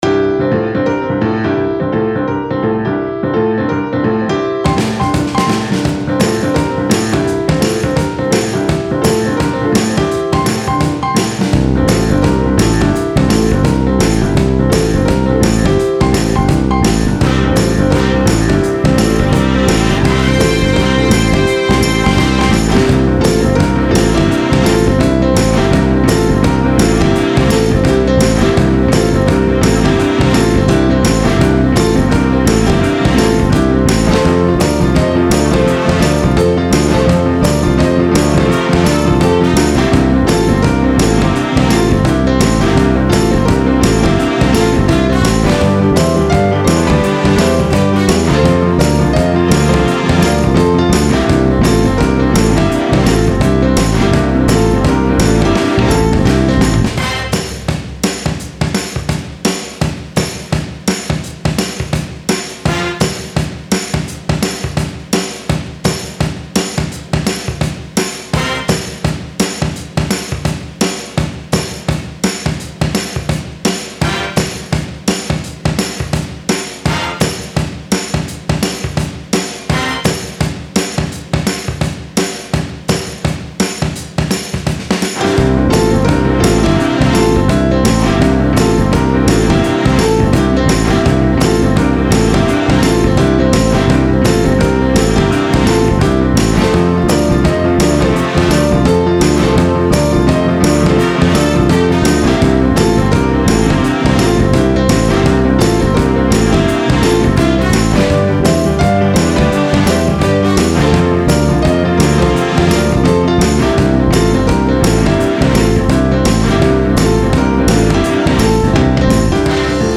Style Style Oldies
Mood Mood Bright, Cool, Driving +1 more
Featured Featured Bass, Brass, Drums +3 more
BPM BPM 169